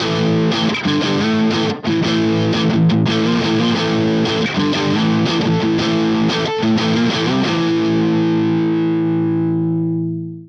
Marshall style Amp und Overdrive
gitmarshallstyleamp_2.wav